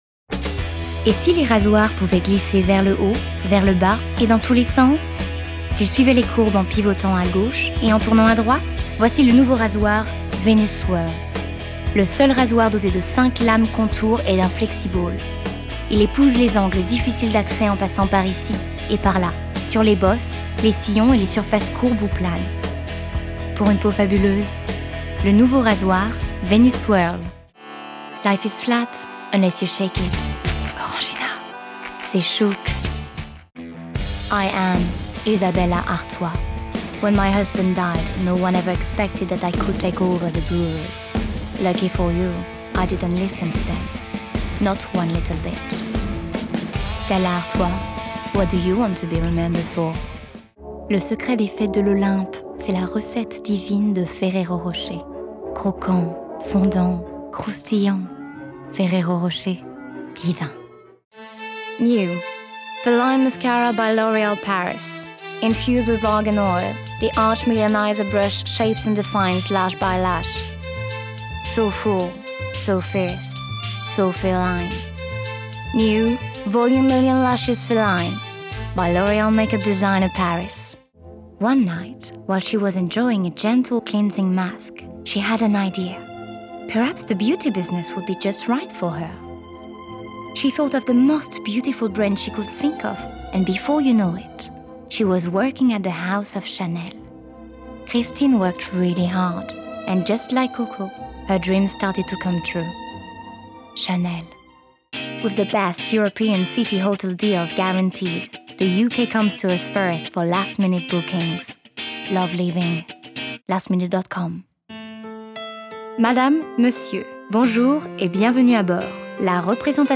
Voice reel
Voix - Soprano